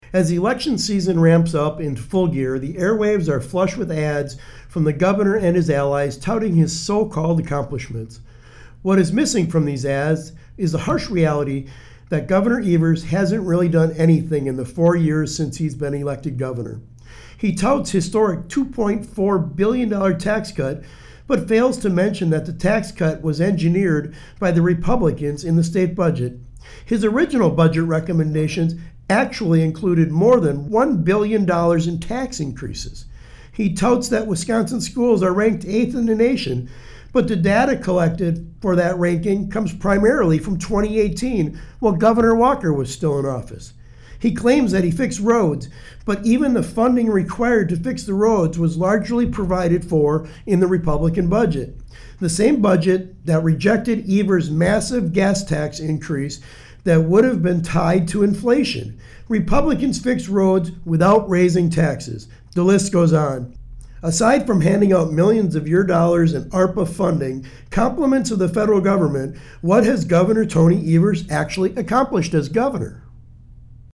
Weekly GOP radio address: Sen. Feyen says Governor Evers has no real accomplishments - WisPolitics